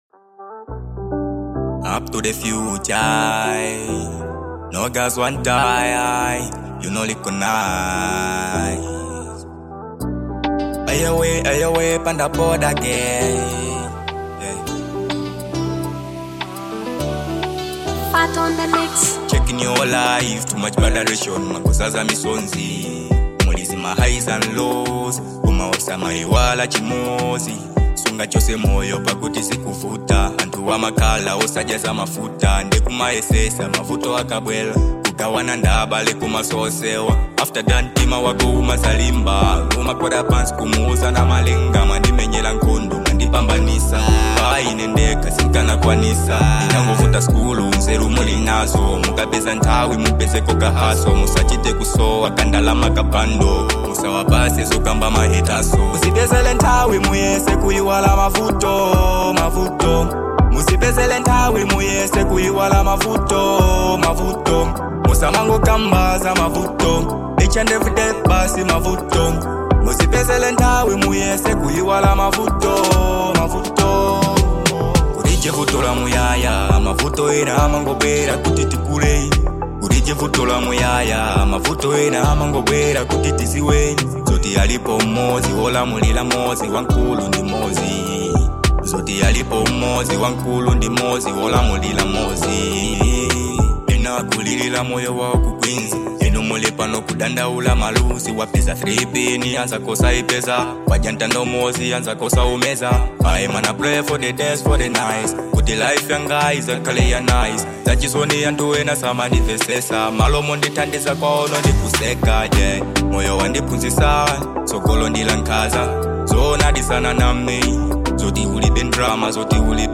Genre : Afro Dancehall